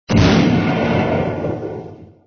s.explosion_tnt.wav